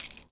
R - Foley 20.wav